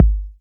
Good Kick One Shot G# Key 593.wav
Royality free kickdrum tuned to the G# note. Loudest frequency: 134Hz
good-kick-one-shot-g-sharp-key-593-zLq.mp3